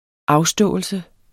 Udtale [ -ˌsdɔˀəlsə ]